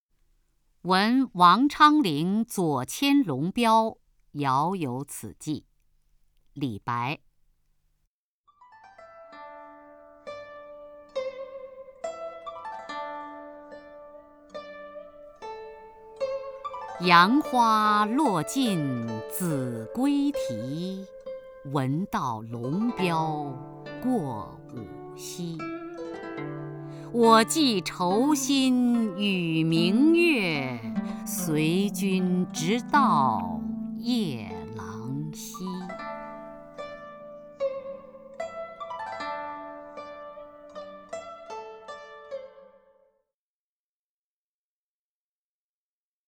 首页 视听 名家朗诵欣赏 雅坤
雅坤朗诵：《闻王昌龄左迁龙标遥有此寄》(（唐）李白)　/ （唐）李白